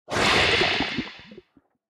Minecraft Version Minecraft Version 1.21.5 Latest Release | Latest Snapshot 1.21.5 / assets / minecraft / sounds / entity / squid / squirt1.ogg Compare With Compare With Latest Release | Latest Snapshot
squirt1.ogg